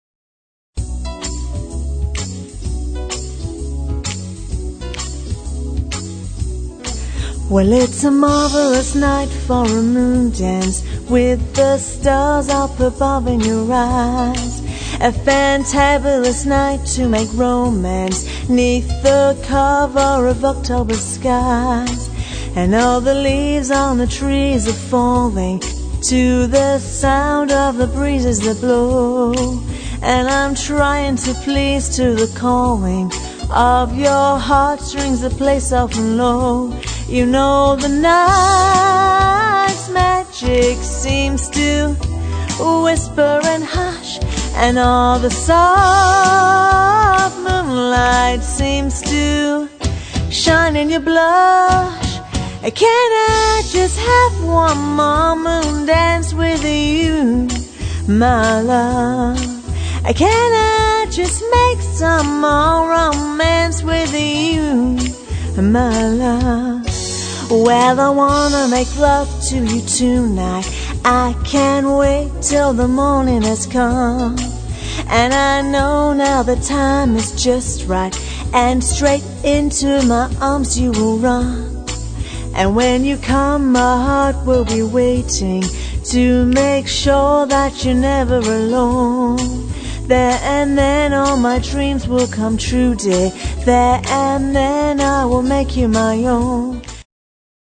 • Six-piece band
• Two female lead vocalists
Jazz